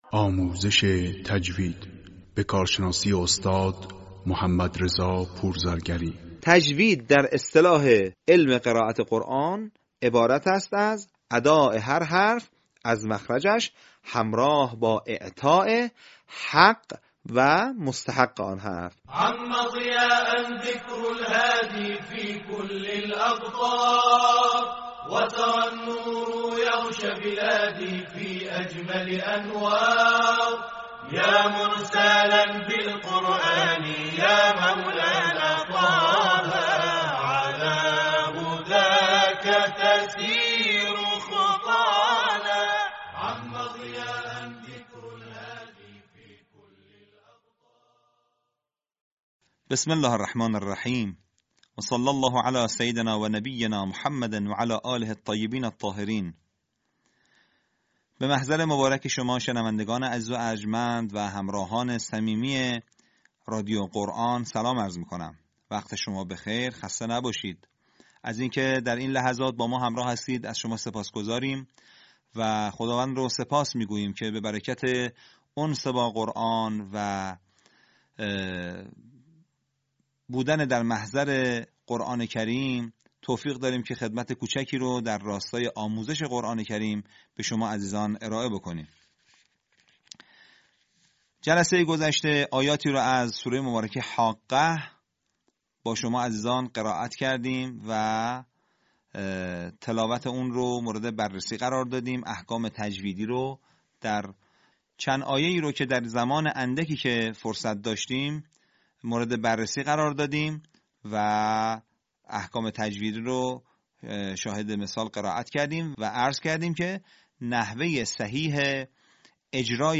صوت | آموزش نکات تجویدی